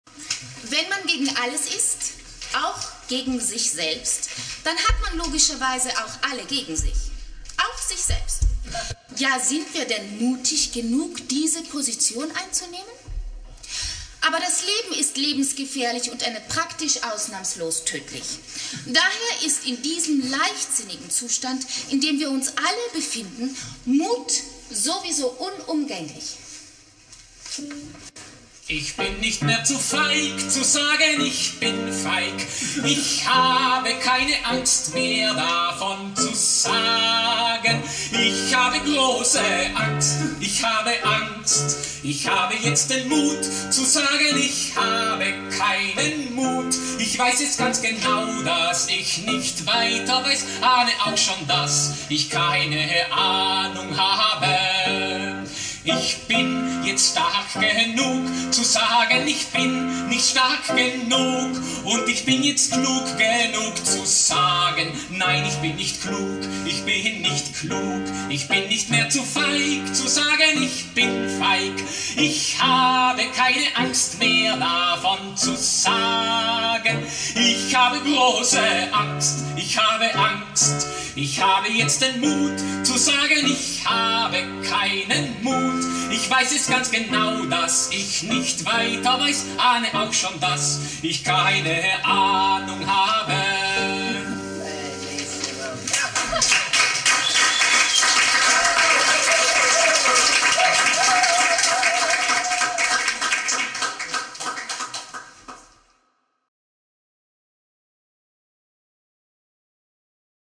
Mut und Sicherheit - hier einmal ersungen!
Es handelt sich um eine Live-Aufnahme während einer privaten Soiree, die wir für das WEB stark komprimiert haben, wir bitten um Nachsicht bei der Tonqualität – es geht uns hier nicht um HiFi, sondern um Inhalt!
Klavier, Gesang